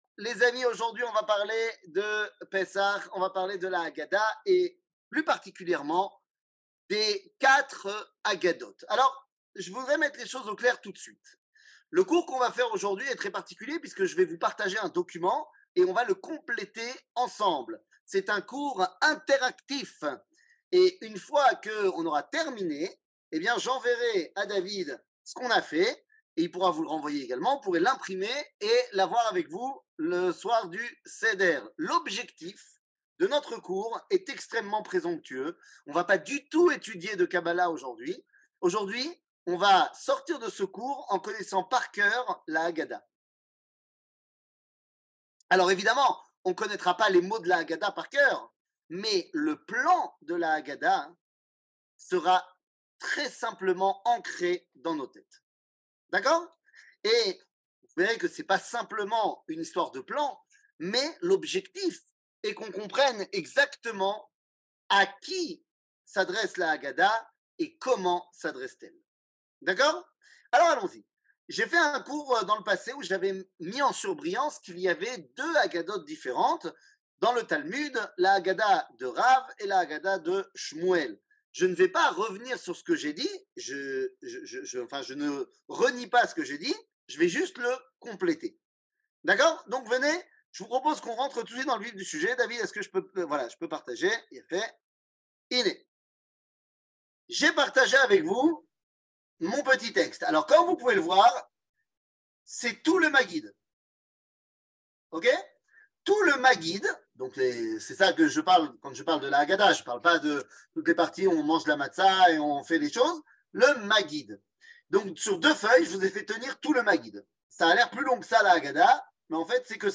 קטגוריה Les 4 haggadots 00:54:27 Les 4 haggadots שיעור מ 02 אפריל 2023 54MIN הורדה בקובץ אודיו MP3